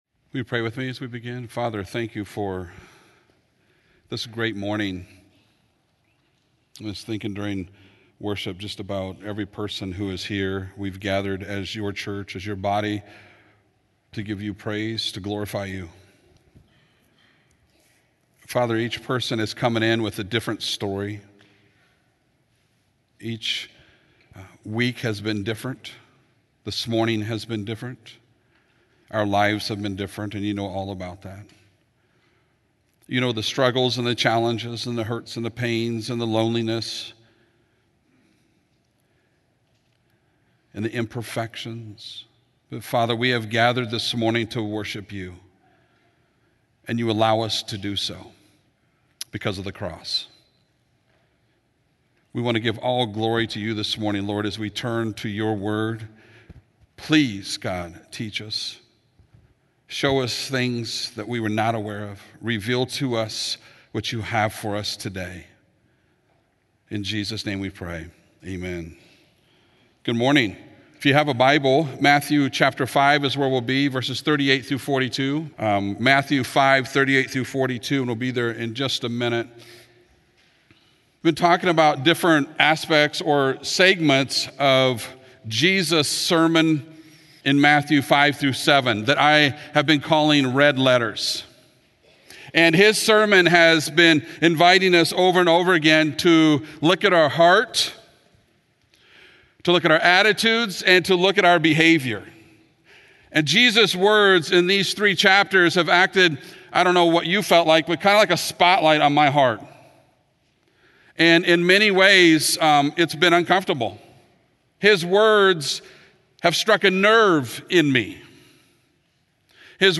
Continuing in our Red Letters sermon series, we come to Jesus’ comments about truth telling in the kingdom of heaven. His message here is a part of Jesus’ longest recorded sermon in the New Testament.